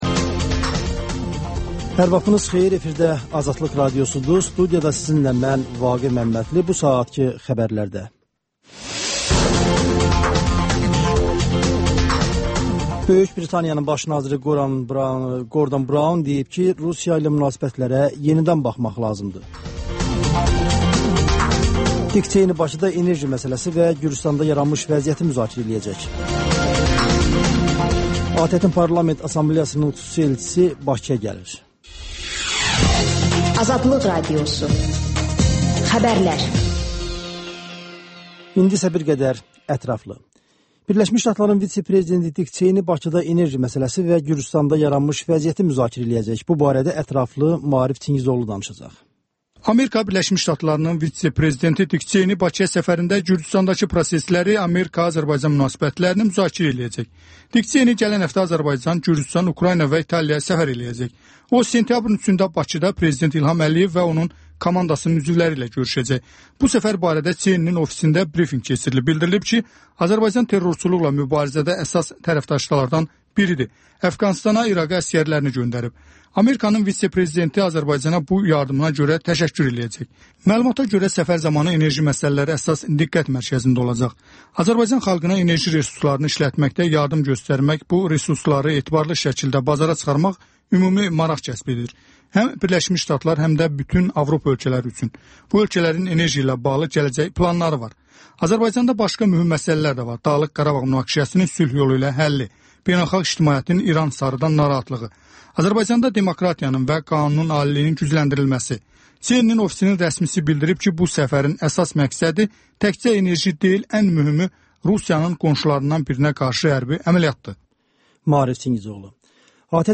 Xəbərlər, QAFQAZ QOVŞAĞI: «Azadlıq» Radiosunun Azərbaycan, Ermənistan və Gürcüstan redaksiyalarının müştərək layihəsi, sonda QAYNAR XƏTT: Dinləyici şikayətləri əsasında hazırlanmış veriliş